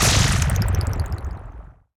explosionWhite.wav